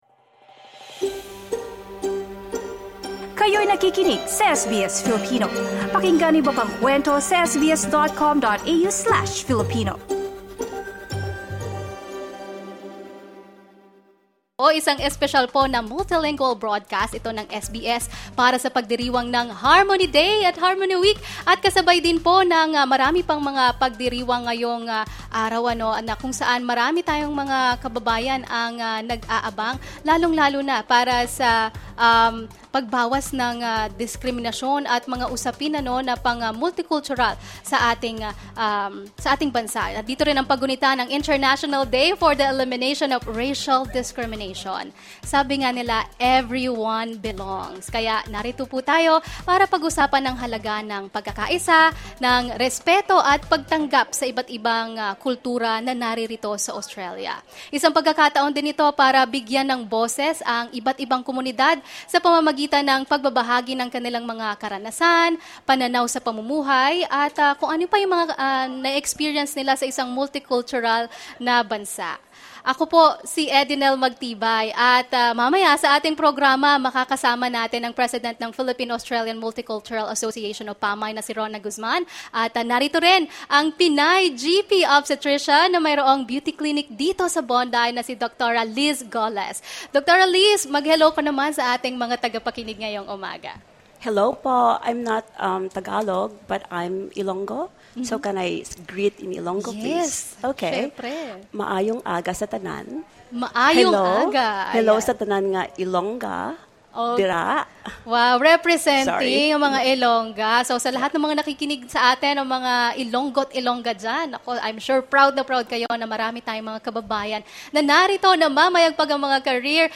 SBS Filipino is one of the language programs that broadcasts live in Bondi Pavilion alongside Italian, Mandarin, Hebrew, Arabic and other language services this March 21.